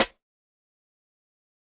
Snares